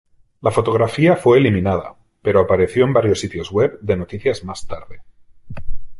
Pronúnciase como (IPA)
/ˈweb/